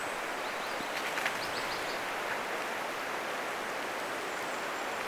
lehtokurppa nousee tässä lentoon
lehtokurppa_nousee_lentoon_siivet_osuvat_ilmeisesti_kasveihin_ja_siita_osa_aanista_tulee.mp3